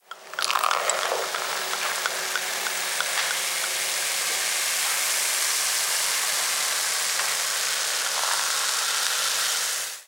Espuma de una botella de cava
burbuja
Sonidos: Especiales
Sonidos: Alimentación